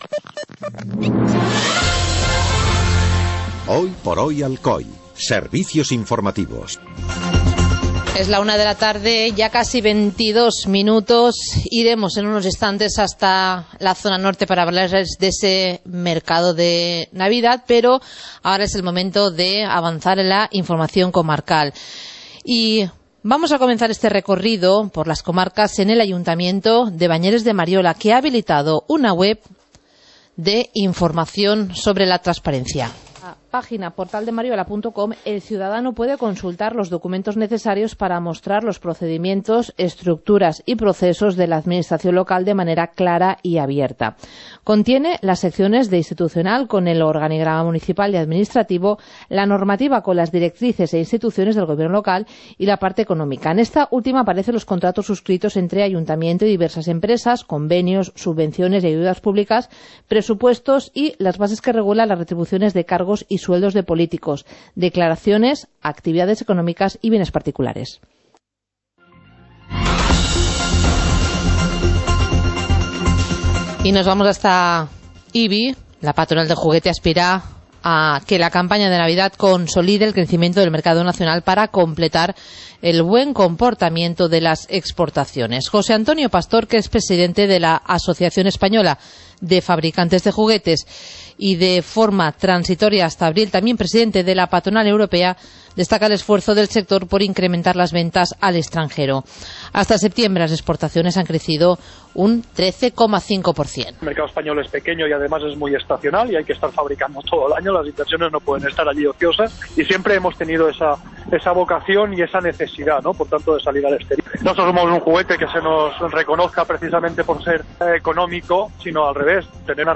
Informativo comarcal - viernes, 11 de diciembre de 2015